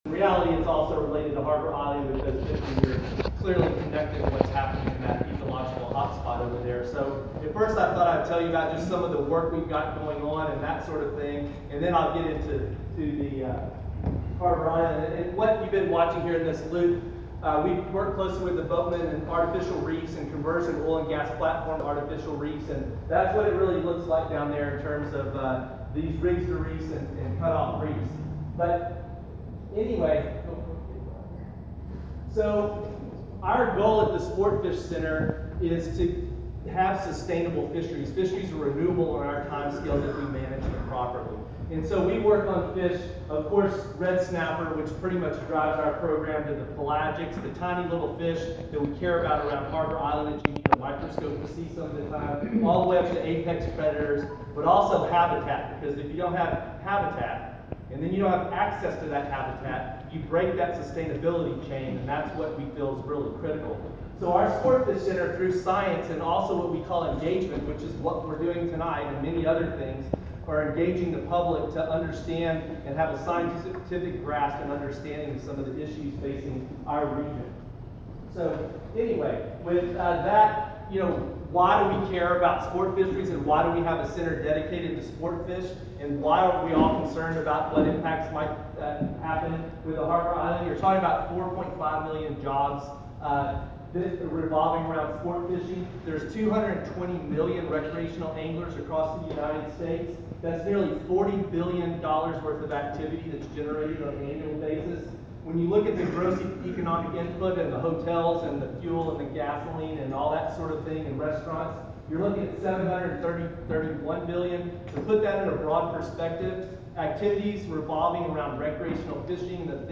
presentation